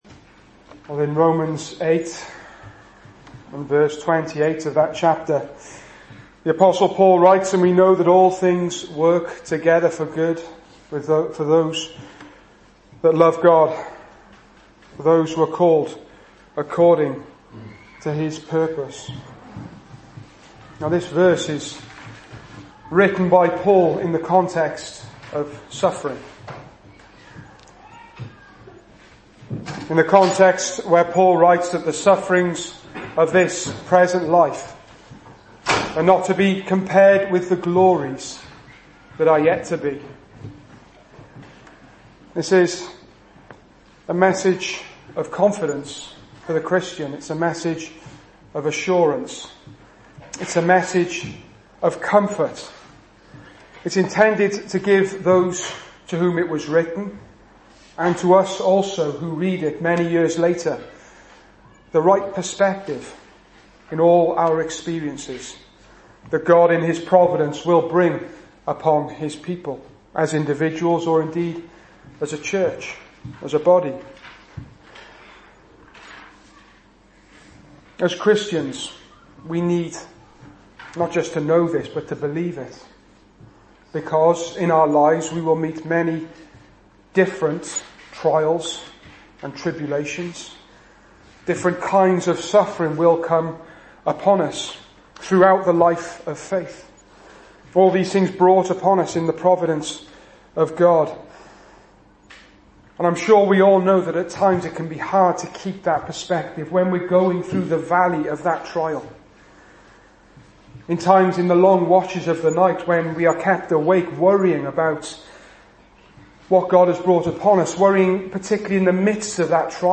2015 Service Type: Sunday Evening Speaker